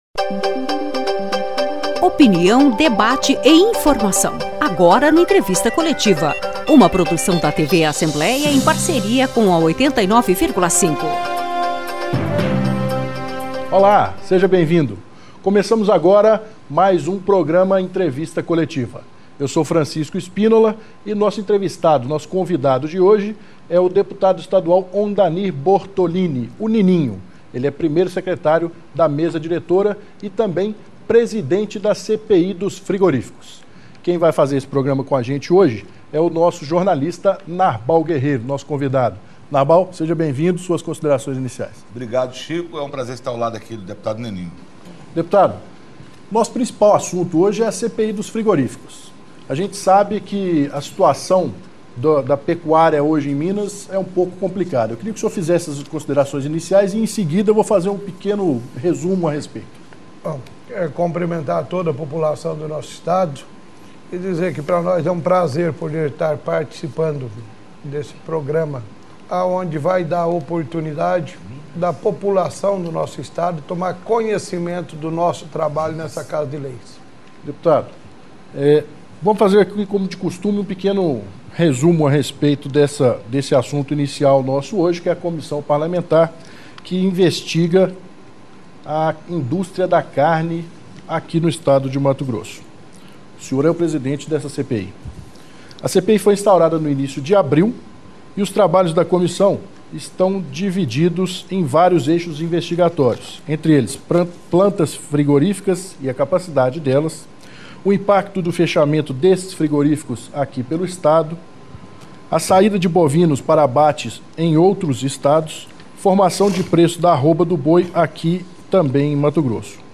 Entrevista Coletiva: Dep. Nininho (PSD)